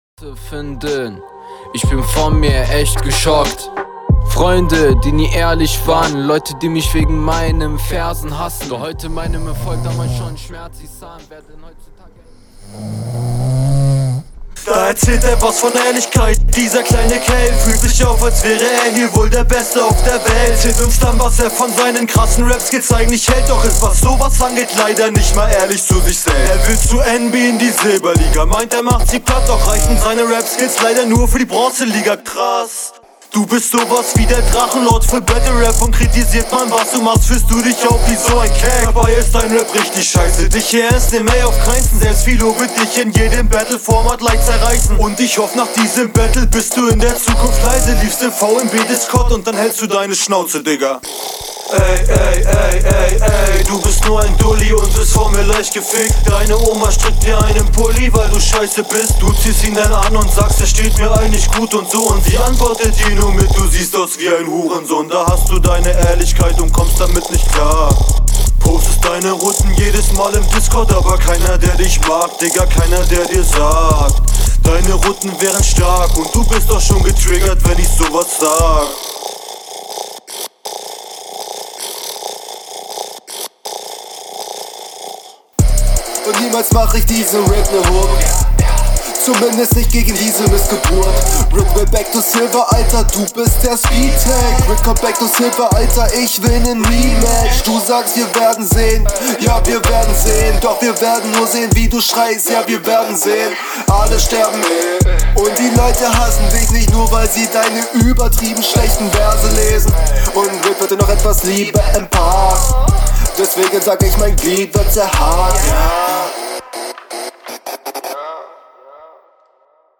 Beat gefällt mir nicht so.